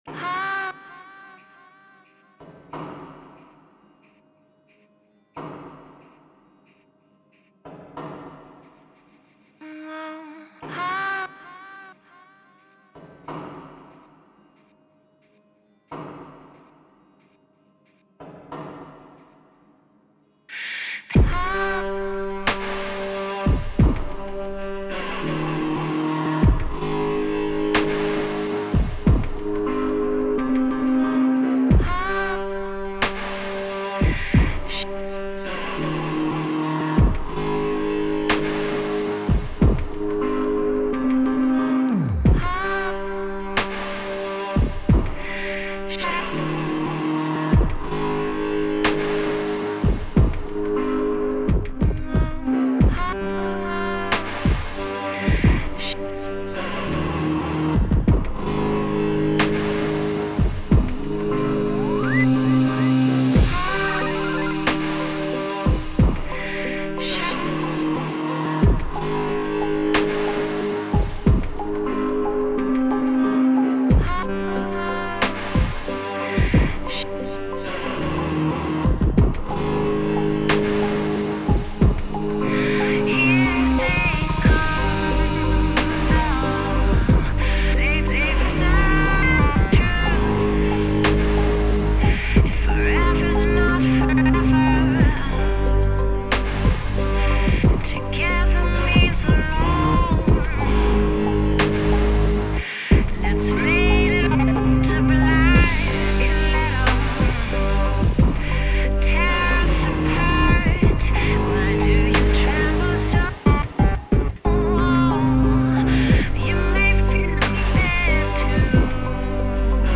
Version mixé finale
disc jockey, compositeur et beat maker canadien.